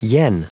Prononciation du mot yen en anglais (fichier audio)
Prononciation du mot : yen